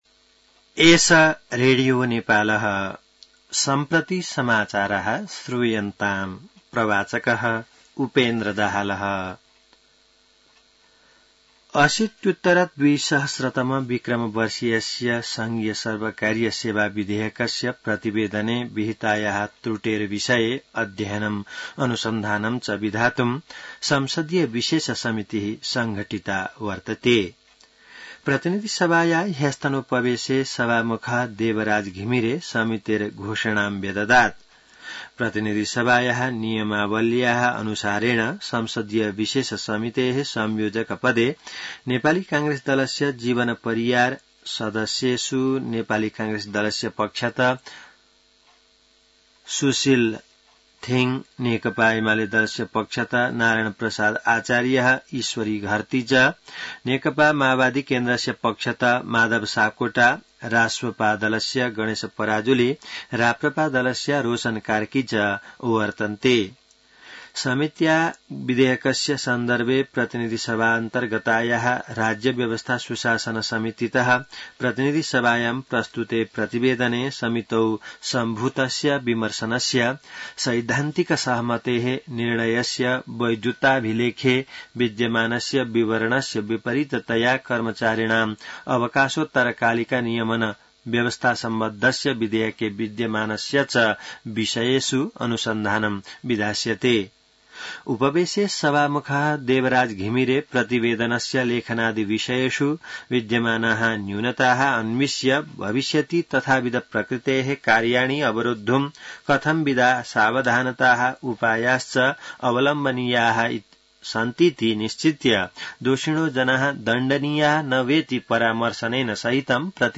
संस्कृत समाचार : २४ असार , २०८२